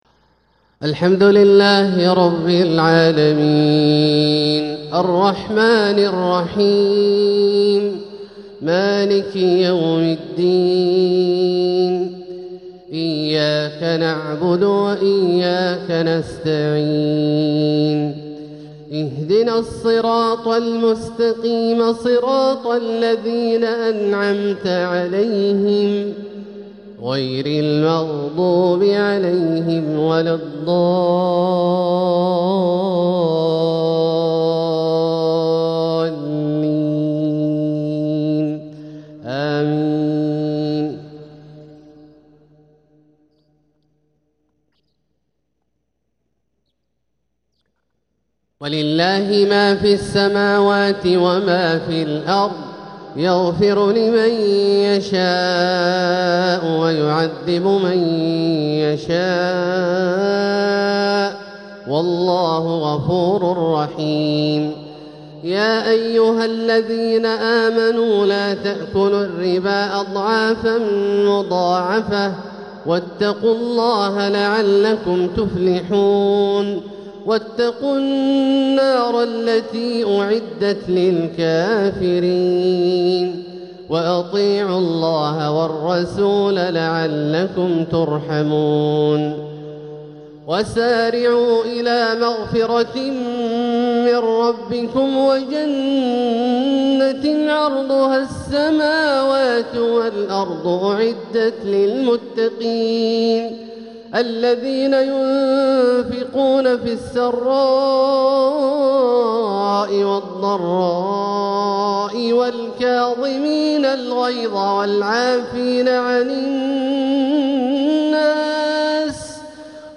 { إن يمسـسكم قرح فقد مس القوم قرح مثله } تلاوة من سورة آل عمران | فجر الأحد 6-4-1447هـ > ١٤٤٧هـ > الفروض - تلاوات عبدالله الجهني